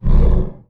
MONSTERS_CREATURES
ORCH_Breath_01_mono.wav